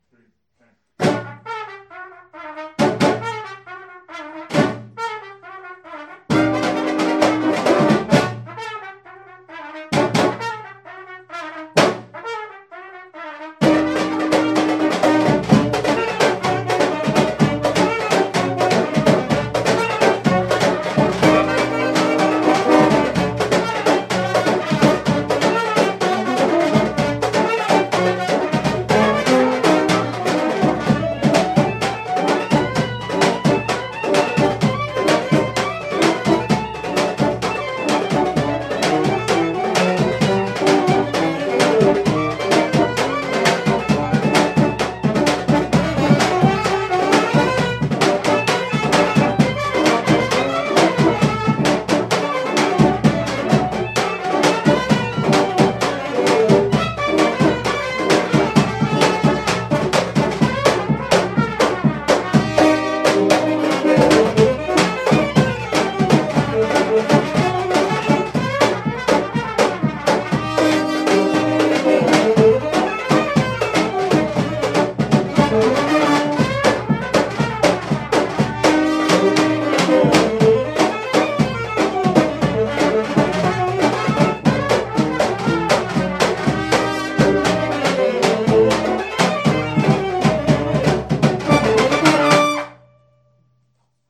Rehearsal Recordings